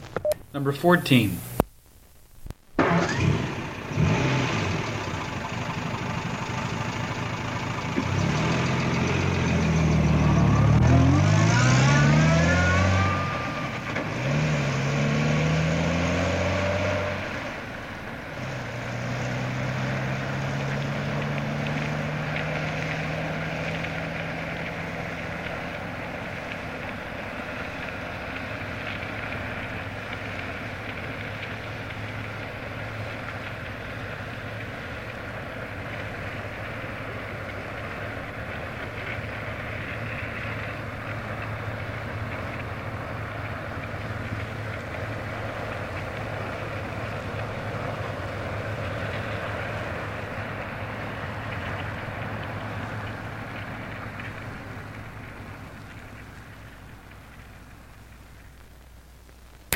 老式卡车 " G1414老式卡车的启动和退出
描述：卡车转动和咔哒作响，因为它空转，换档，它会带走一些碎石和风声。 这些是20世纪30年代和20世纪30年代原始硝酸盐光学好莱坞声音效果的高质量副本。 40年代，在20世纪70年代早期转移到全轨磁带。我已将它们数字化以便保存，但它们尚未恢复并且有一些噪音。
Tag: 卡车 交通运输 光学 经典